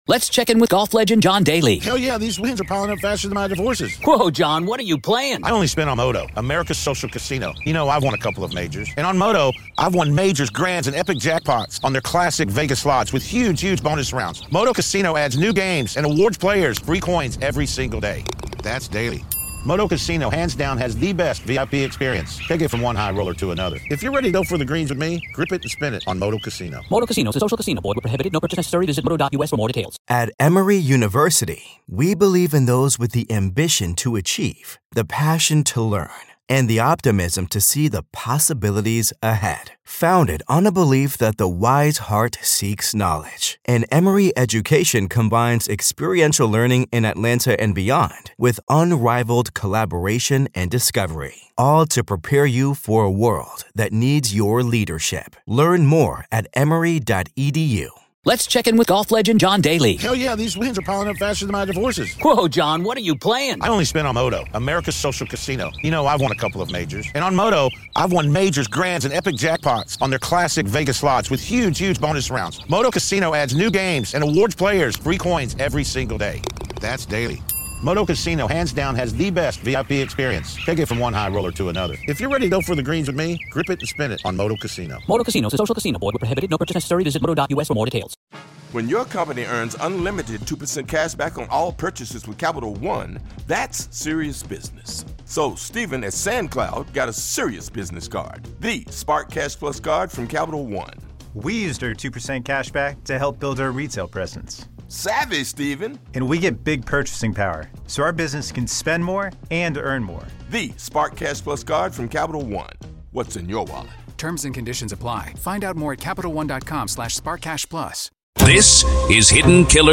In this powerful conversation